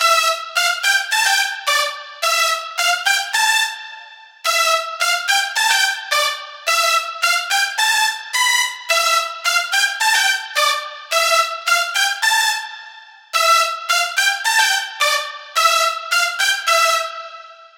合成器主旋律
描述：虚拟仪器：NI Massive Exakt 音阶：C Dorian
Tag: 54 bpm Hip Hop Loops Synth Loops 3.02 MB wav Key : C Cubase